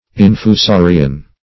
Search Result for " infusorian" : Wordnet 3.0 NOUN (1) 1. any member of the subclass Infusoria ; The Collaborative International Dictionary of English v.0.48: Infusorian \In`fu*so"ri*an\, n. (Zool.)